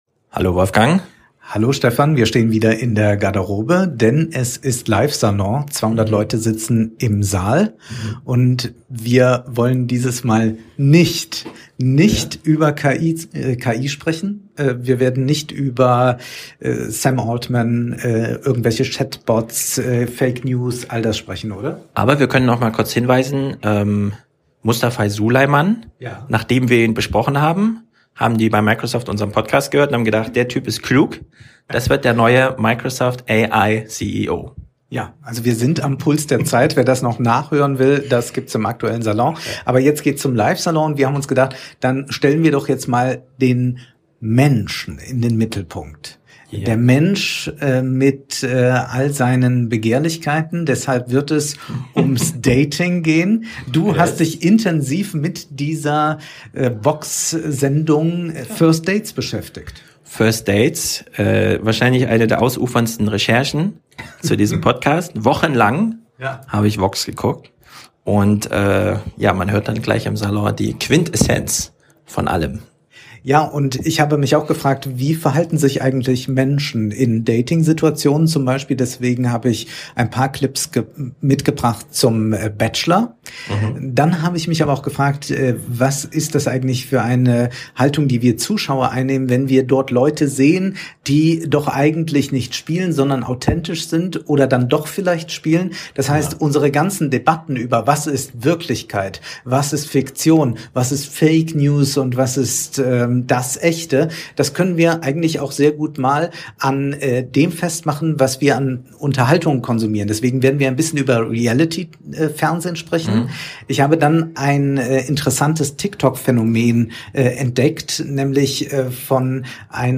Vorm Salon, Dieses eine Leben, Live in Frankfurt